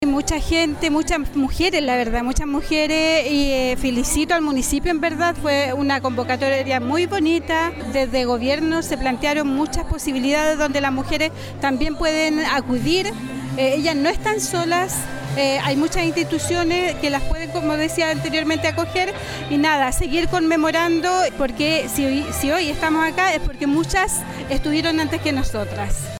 La actividad, realizada en el Centro Cultural, reunió a más de 300 asistentes y contó con la presencia de autoridades locales y regionales, quienes destacaron la importancia de la equidad de género y el rol esencial de la mujer en la comunidad.